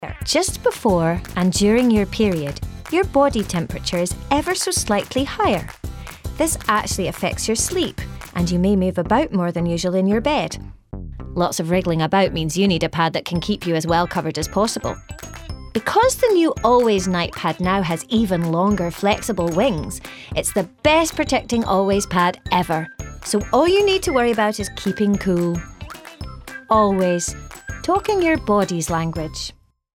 RP. Versatile characters, many accents & standard English. Bright, young, upbeat ads, also a singer.